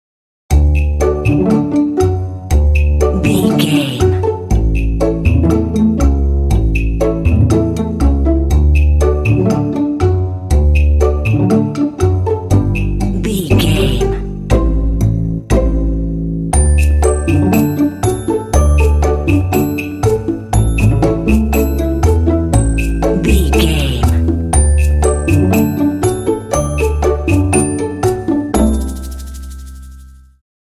Uplifting
Ionian/Major
D
happy
bright
joyful
double bass
strings
conga
contemporary underscore